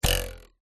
Звуки линейки
Дернули один раз